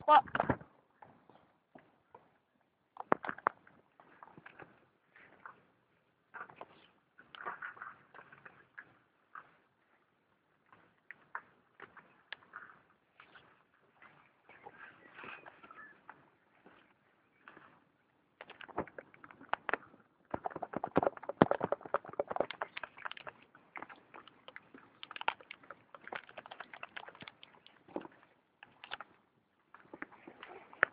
pluie